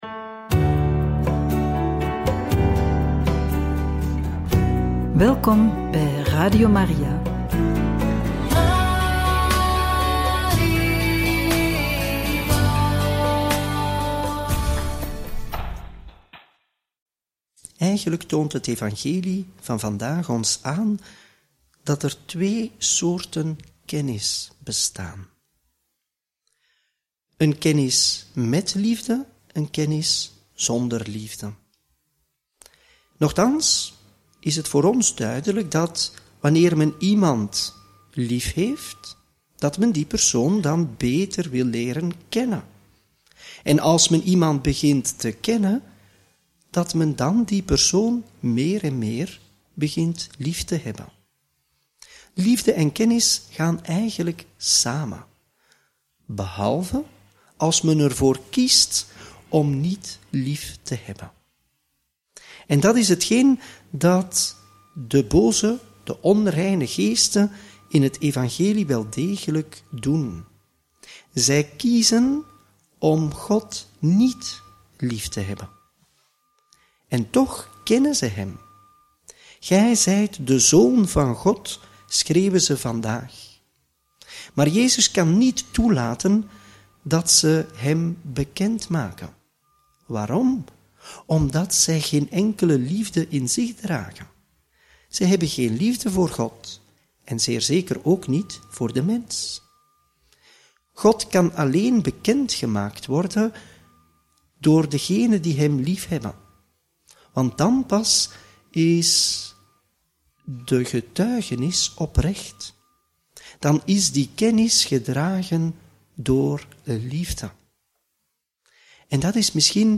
Homilie bij het Evangelie van donderdag 23 januari 2025 - Marcus 3, 7-12